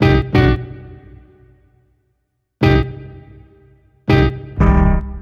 Back Alley Cat (Tone Hit 02).wav